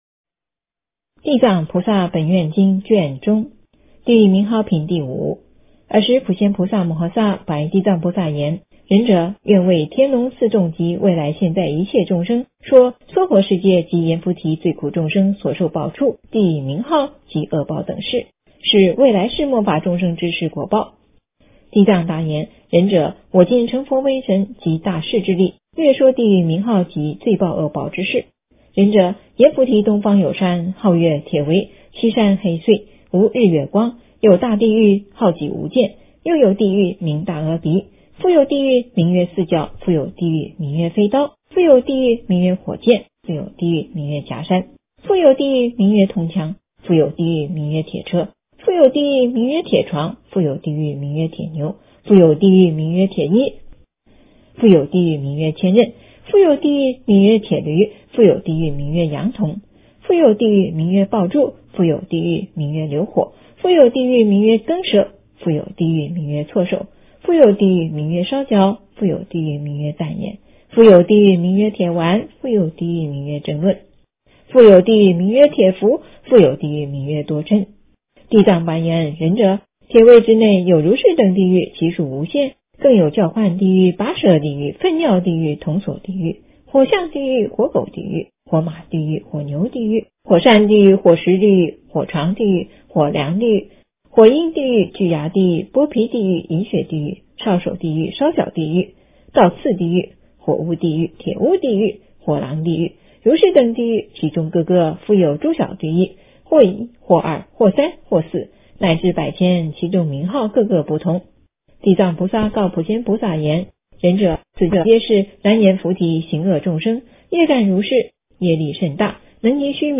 诵经
佛音 诵经 佛教音乐 返回列表 上一篇： 妙法莲华观世音菩萨普门品 下一篇： 地藏经-赞叹品第六 相关文章 六字大明咒--Monjes Budistas 六字大明咒--Monjes Budistas...